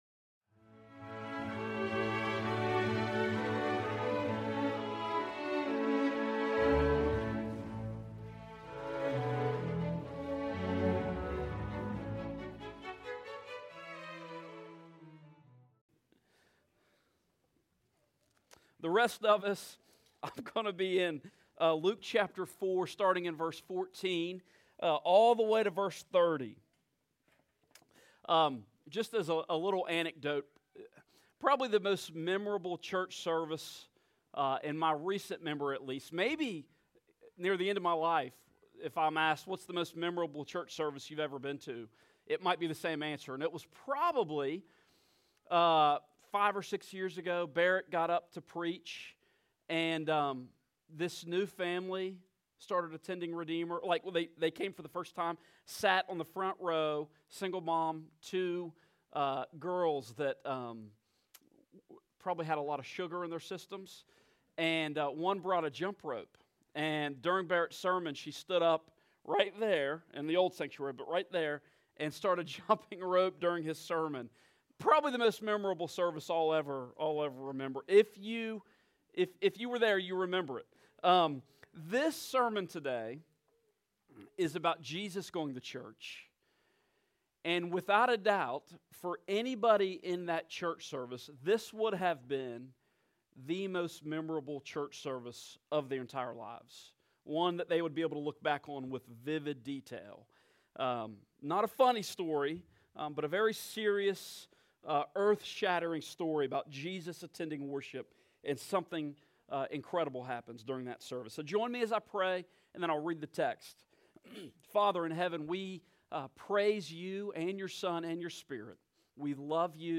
Service Type: Morning Service
Sermon-Intro_Joined-1.mp3